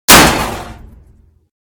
/ gamedata / sounds / material / bullet / collide / metal05gr.ogg 18 KiB (Stored with Git LFS) Raw History
metal05gr.ogg